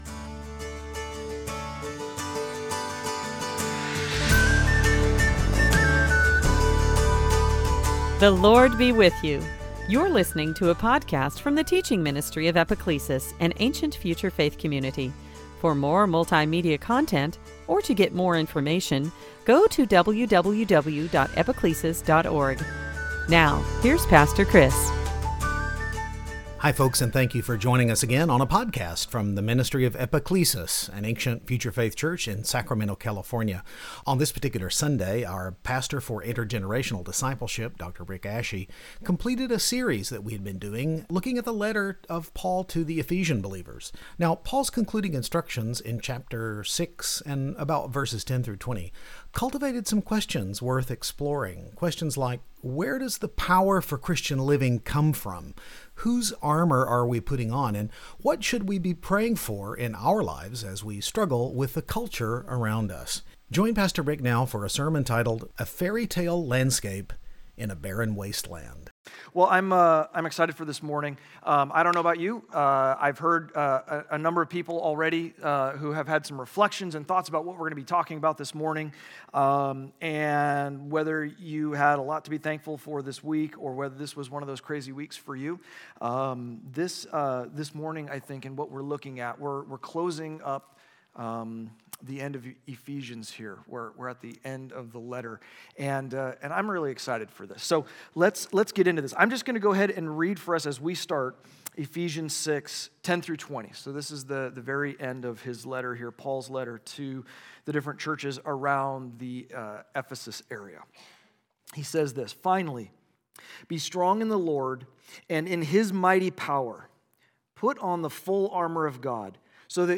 This sermon completes our series through the letter to the Ephesian believers. Paul’s concluding instructions in chapter 6 cultivated some questions worth exploring.